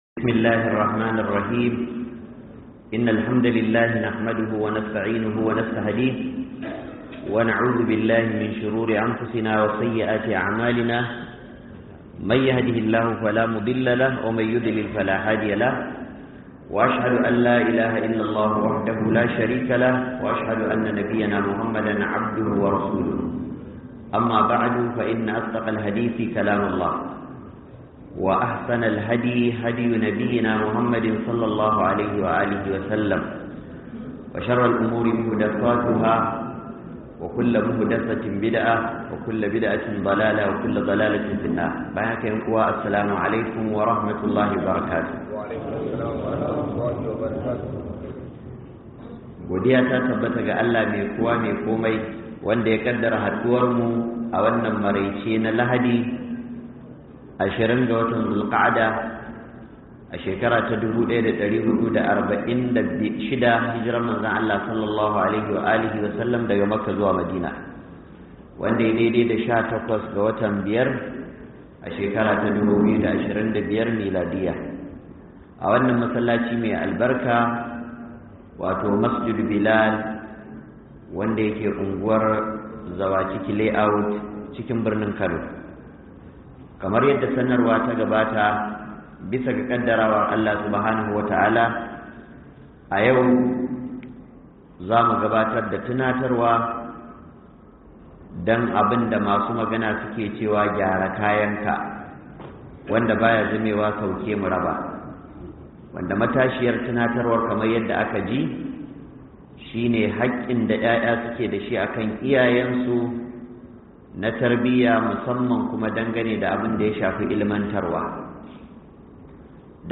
HAKKIN IYAYE NA TARBIYYA DA WAJABCIN BASU ILIMIN ADDININ MUSULUNCI - MUHADARA